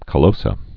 (kə-lōsə)